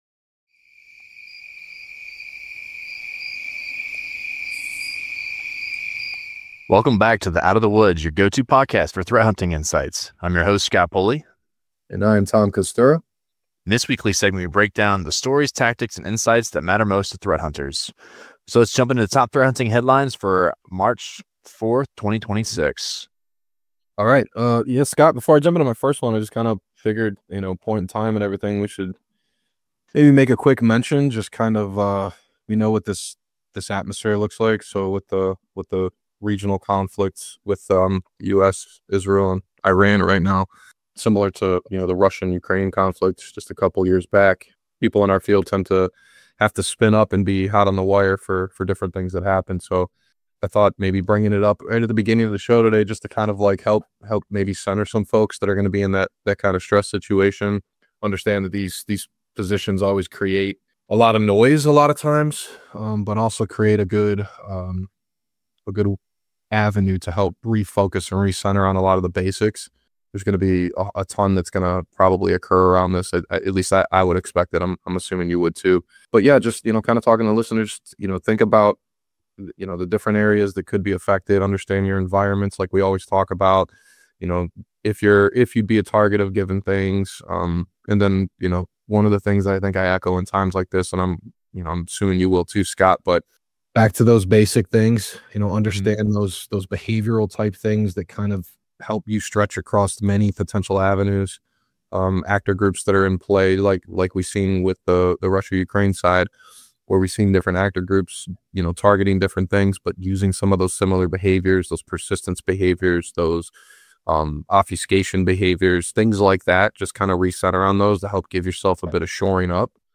The Out of the Woods podcast is a casual talk covering the topics of threat hunting, security research, and threat intelligence, and some ranting and raving along the way, all over a cocktail or two! The Out of the Woods cyber security podcast is filmed in front of a live studio audience, and by that we mean YOU!